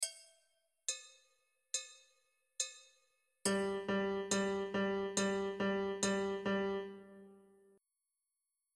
* W tym materiale grane nutki to dźwięk G (wysokość równa zagranej pustej strunie o tej nazwie), ale w dalszych ćwiczeniach będzie to już bardziej konkretne granie :)
Wszystkie przykłady do posłuchania są grane właśnie z klikiem metronomu.
UWAGA! W przykładach do posłuchania jest zawsze najpierw nabicie (1, 2, 3, 4), które pozwala nam złapać tempo ćwiczenia.
ósemki
Jeśli teraz nałożymy "klik" na takt z ósemkami, otrzymamy: